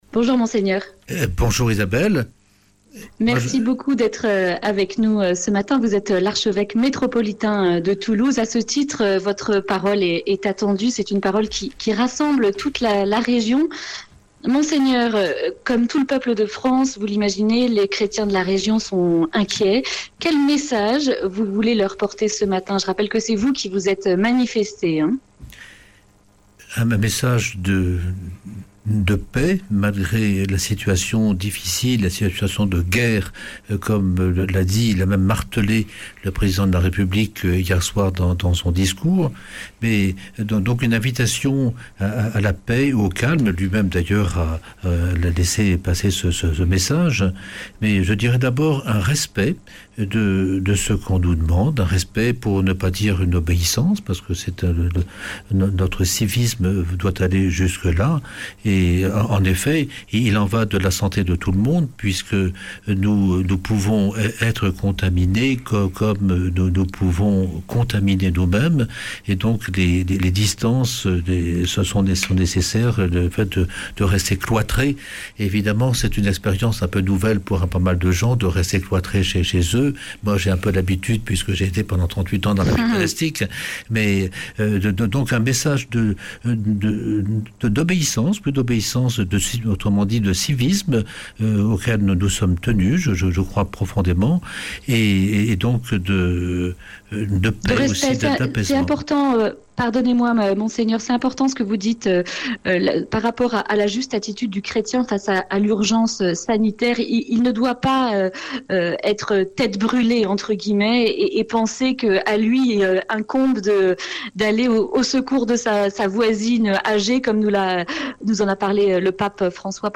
Lien vidéo => Retrouvez cette interview sur Youtube